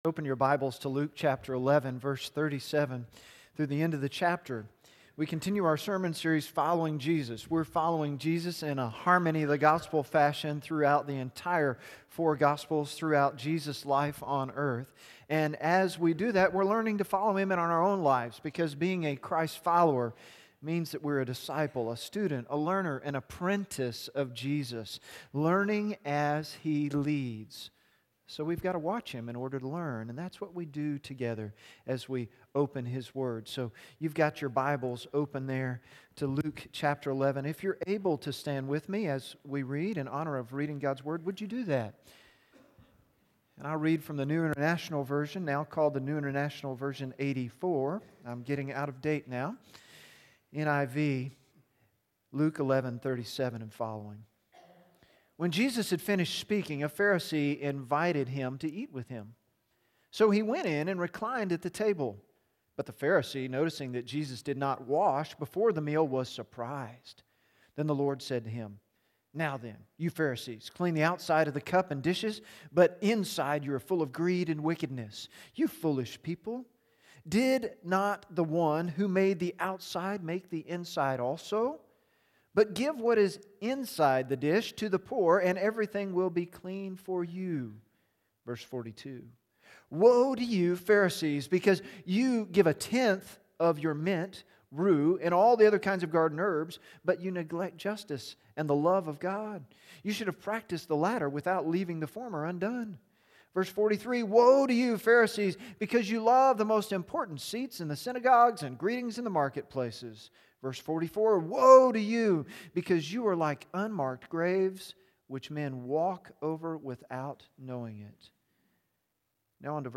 Luke 11:37-54 Sermon Notes on YouVersion Following Jesus: Don't Even Go There